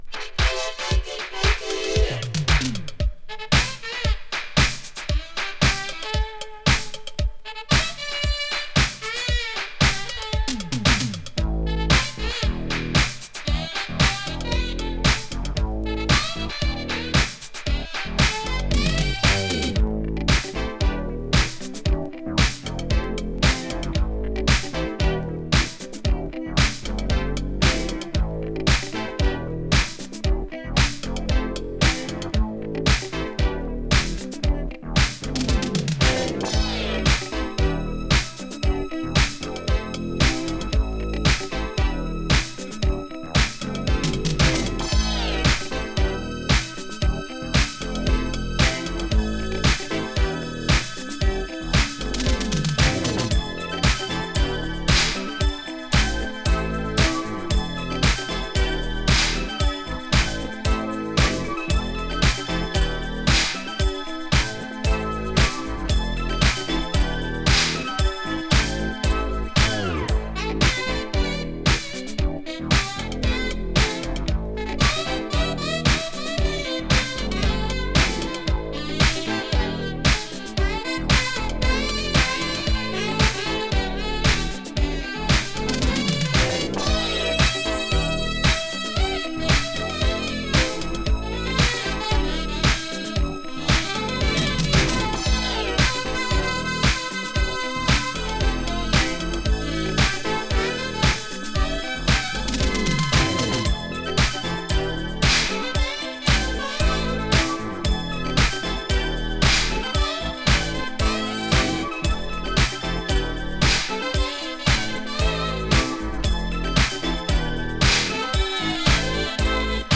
Brit Funk!UK産ディスコ〜ポップ・グループ。
【BOOGIE】